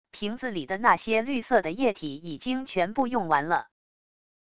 瓶子里的那些绿色的液体已经全部用完了wav音频生成系统WAV Audio Player